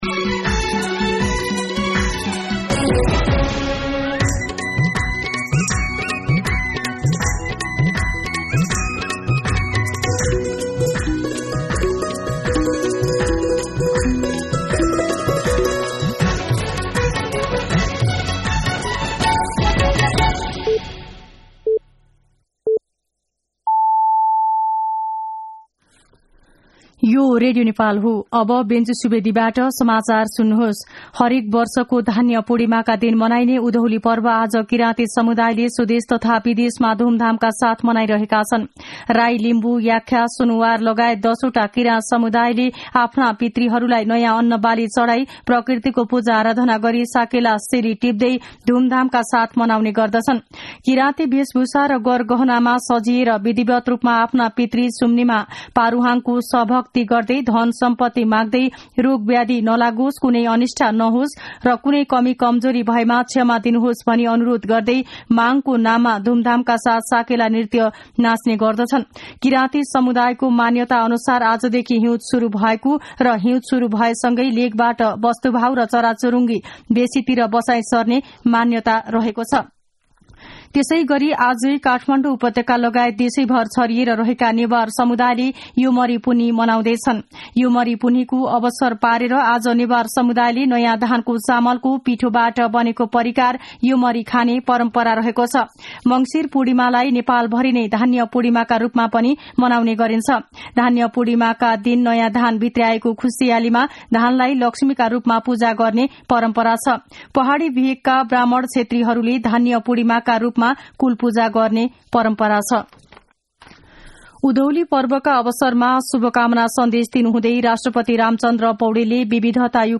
दिउँसो १ बजेको नेपाली समाचार : १ पुष , २०८१
1pm-Nepali-News.mp3